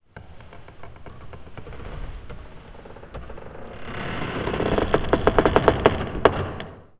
woodcreak2c.wav